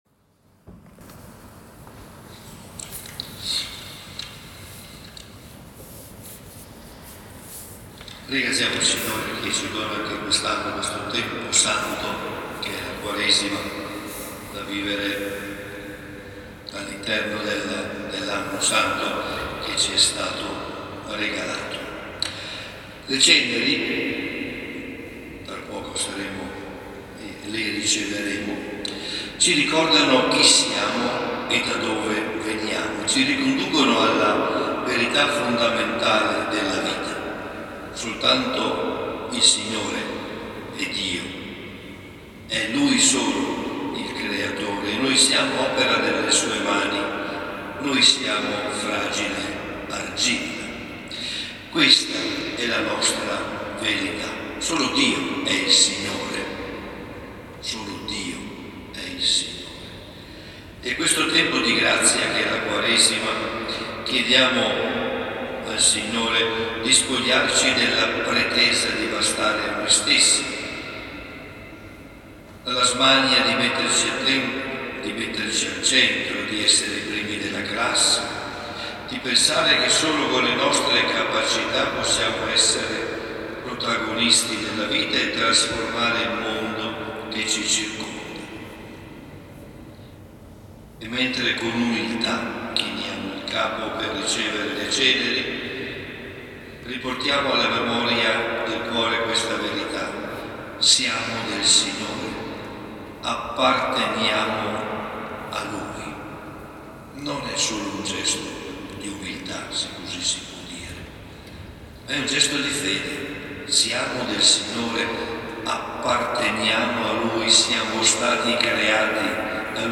Mercoledì 5 marzo in Cattedrale Padre Marco Tasca ha presieduto la S. Messa con l’imposizione delle Ceneri per l’inizio del tempo di Quaresima.